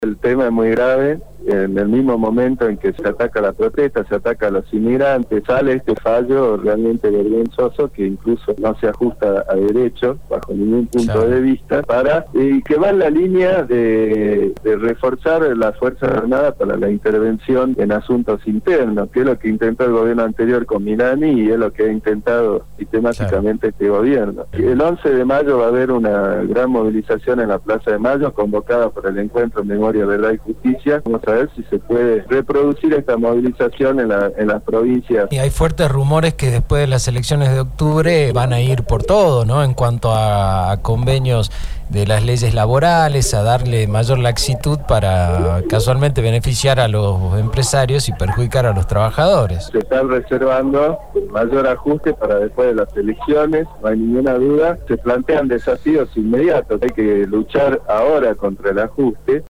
El diputado nacional Pablo López se refirió al fallo polémico de la Corte Suprema que beneficia a ex represores, también a la situación económica del país y dijo que el 11 de mayo va a haber una gran movilización en la Plaza de Mayo.